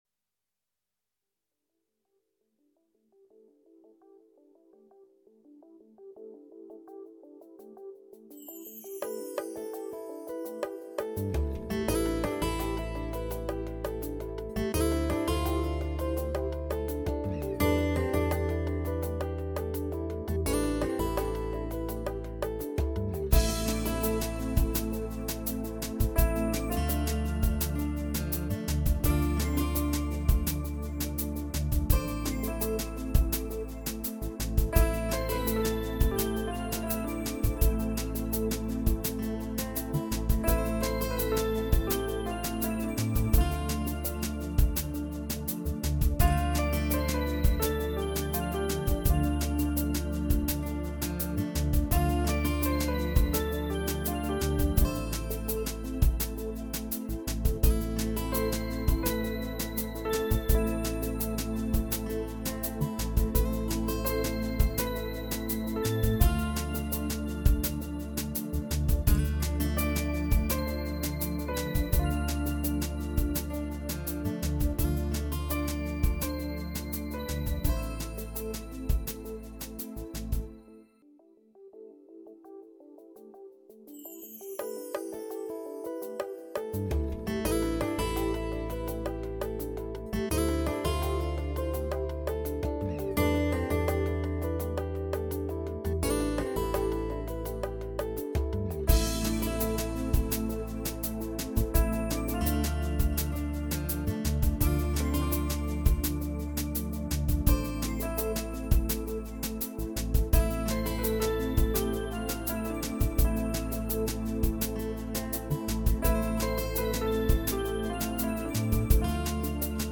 Composizioni che evidenziano temi cantabili e armoniosi.